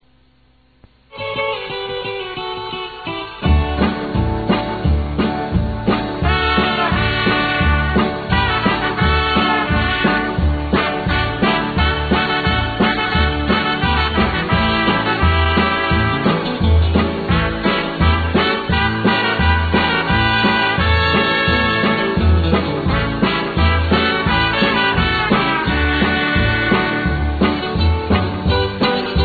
an instrumental for the label
high sound quality
BASSIST